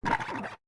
Cri de Chochodile dans Pokémon Écarlate et Violet.